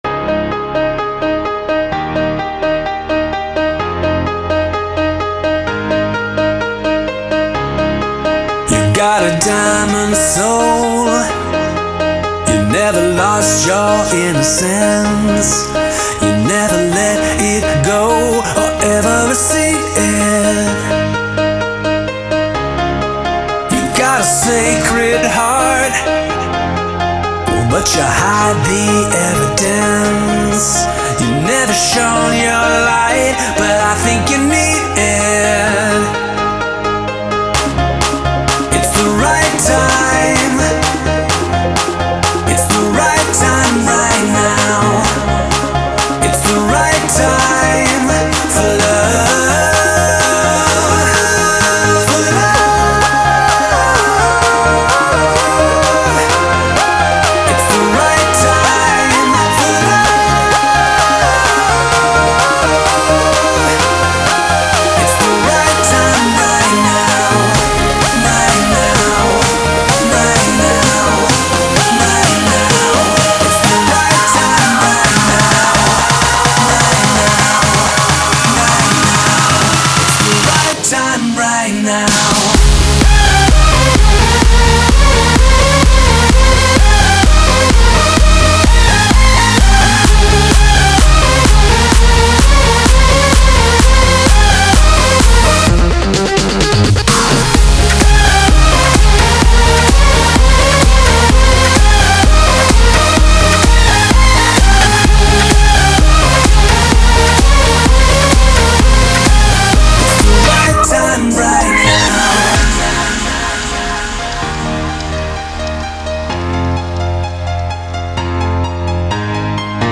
это энергичная танцевальная композиция в жанре EDM
Звучание выделяется яркими мелодиями и ритмичными битами